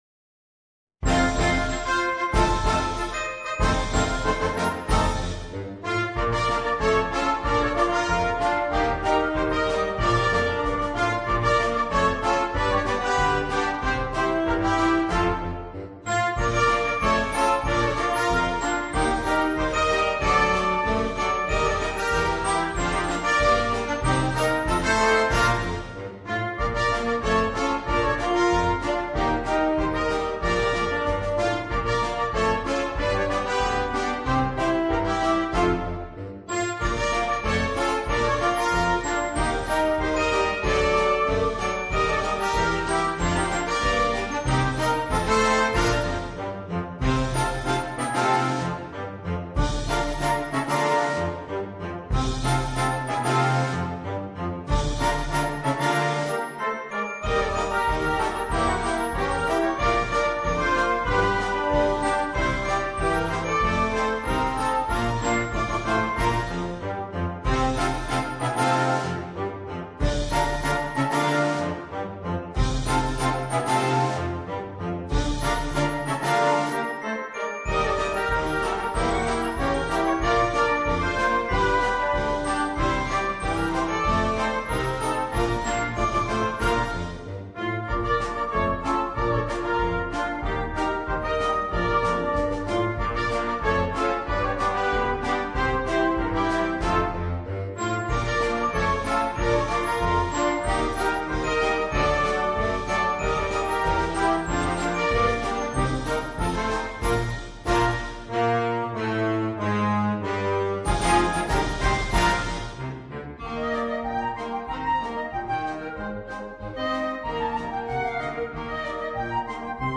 Dixie per banda
Un dixie per banda frizzante per rallegrare il concerto.